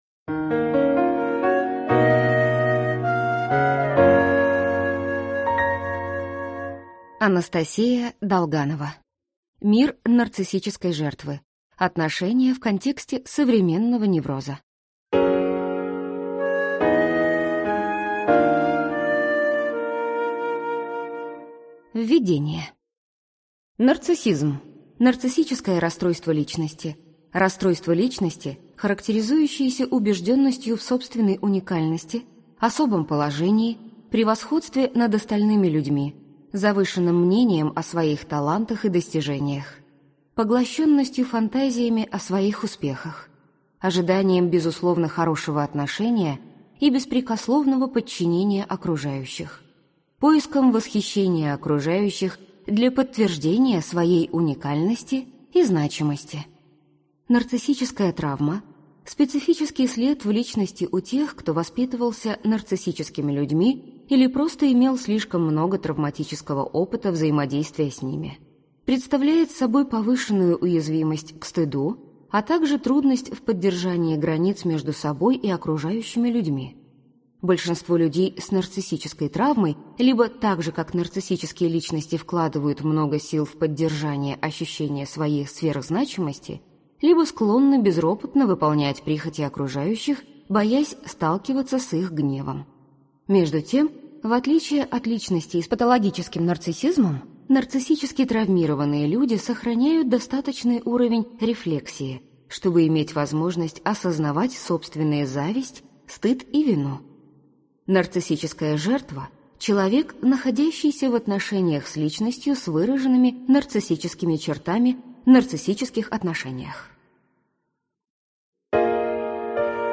Аудиокнига Мир нарциссической жертвы. Отношения в контексте современного невроза | Библиотека аудиокниг